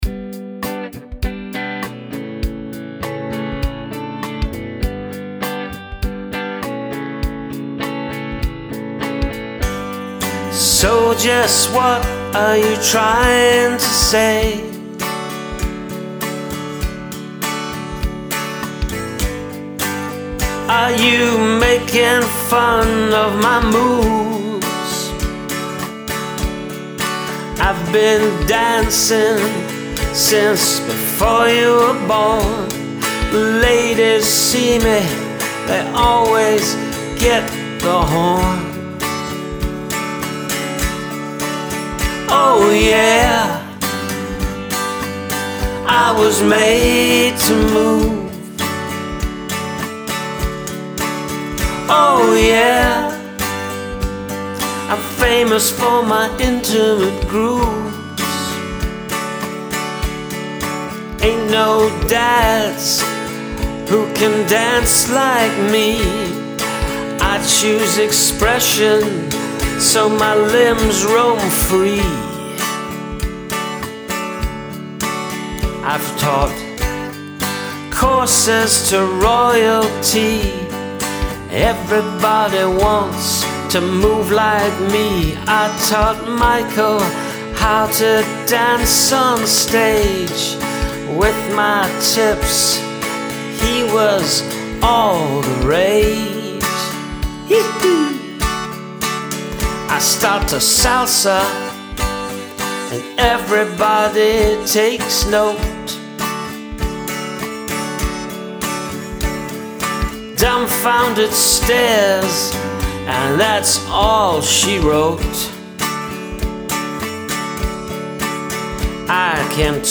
A great song to slow dance to :)
You got the moves and it's a groovy song!